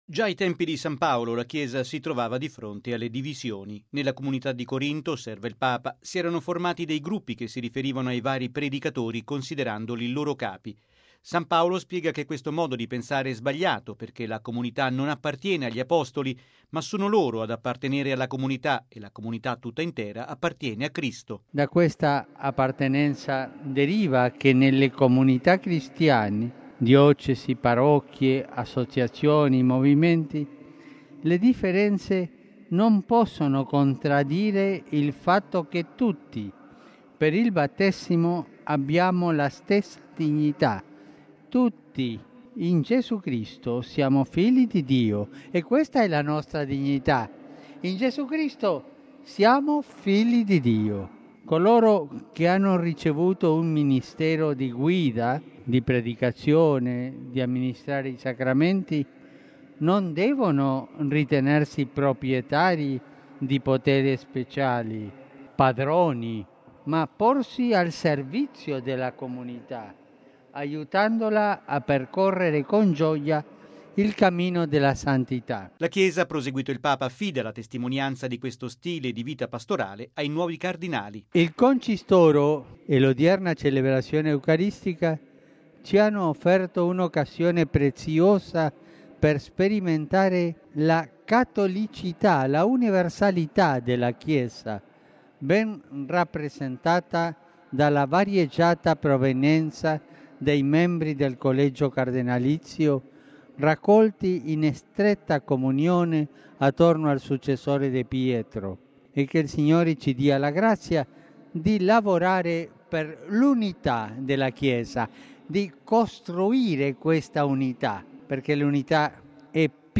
◊   Dopo la celebrazione con i nuovi cardinali, il Papa, a mezzogiorno, ha guidato la tradizionale preghiera dell’Angelus dalla finestra dello studio privato nel Palazzo apostolico. Di fronte ai tanti pellegrini riuniti in Piazza San Pietro, ha esortato a lavorare per l’unità della Chiesa, evitando ogni divisione, perché una comunità non appartiene a qualche predicatore, ma a Cristo.